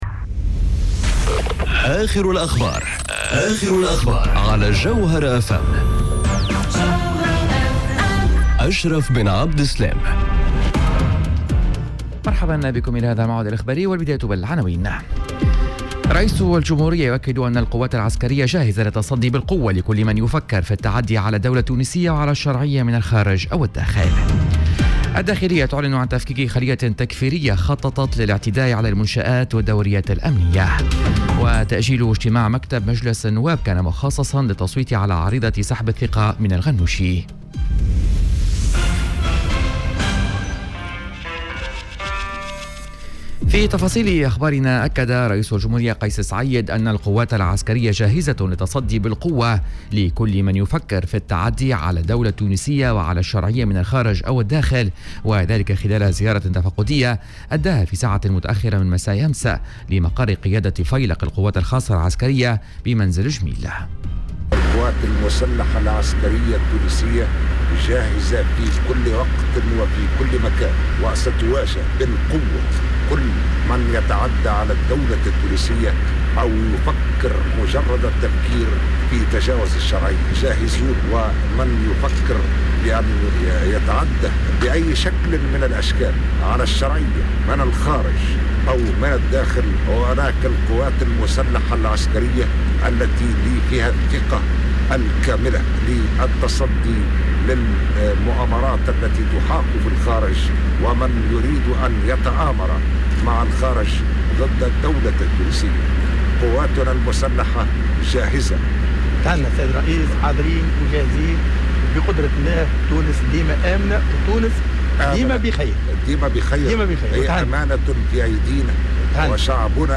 نشرة أخبار منتصف النهار ليوم الإربعاء 22 جويلية 2020